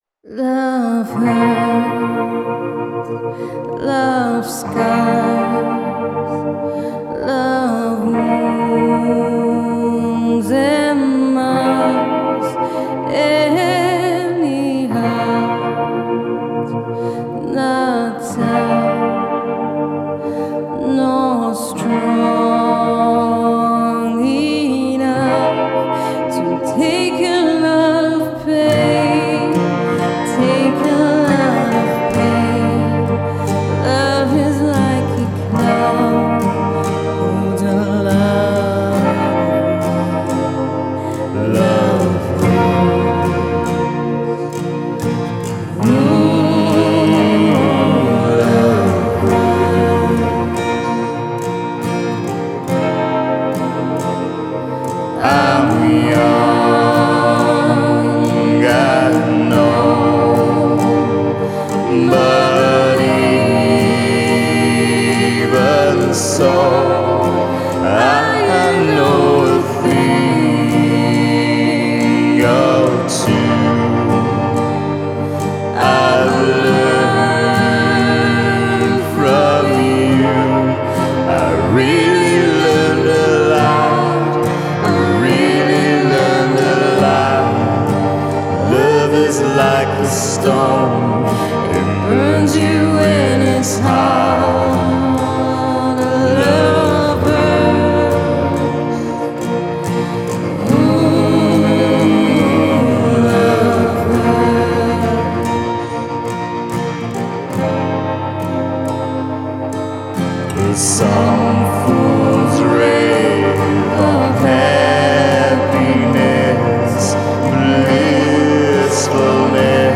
Жанр: Indie Pop
Style: Indie Pop